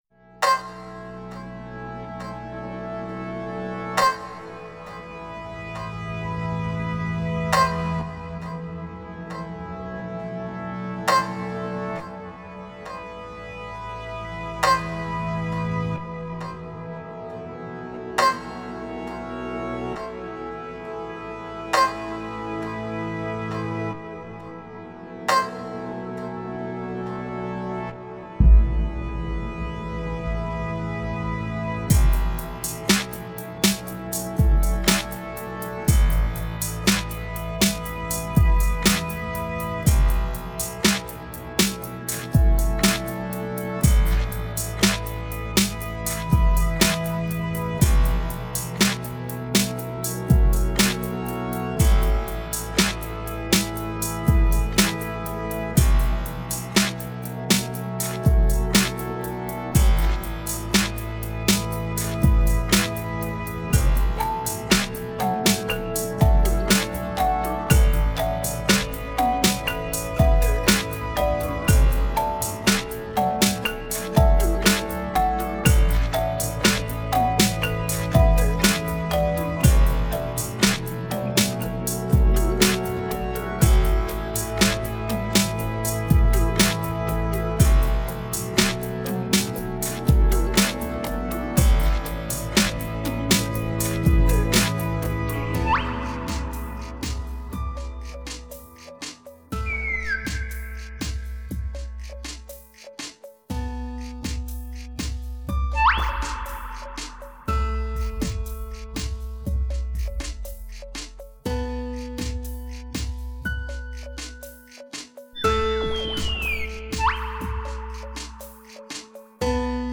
Chillout Mix
Electronic
Electronica
Mood: Party Music